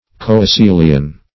coecilian - definition of coecilian - synonyms, pronunciation, spelling from Free Dictionary
Coecilian \C[oe]*cil"i*an\, n. (Zool.)